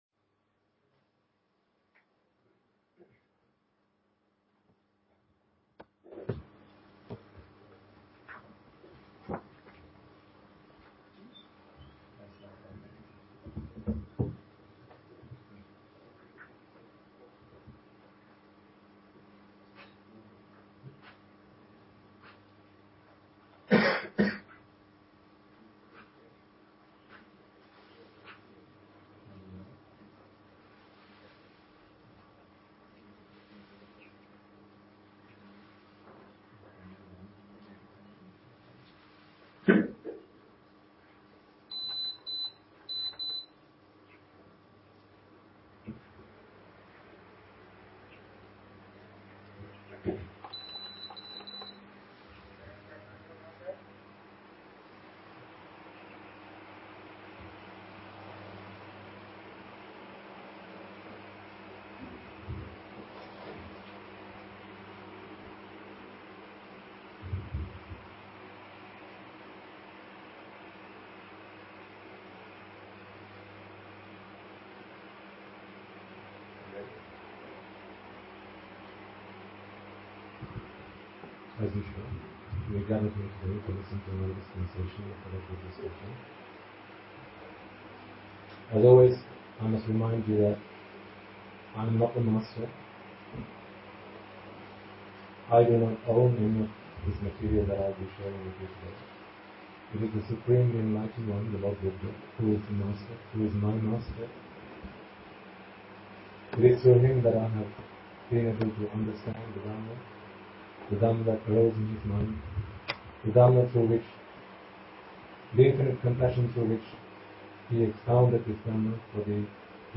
English Dhamma Sermon on 2018-11-25